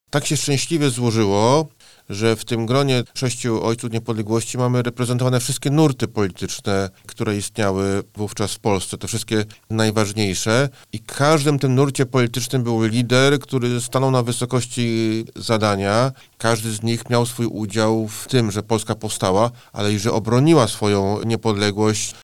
– mówi dr Mateusz Szpytma, zastępca prezesa Instytutu Pamięci Narodowej.
o-Ojcach-Niepodleglosci-1918-dr-Mateusz-Szpytma-zastepca-prezesa-IPN-1.mp3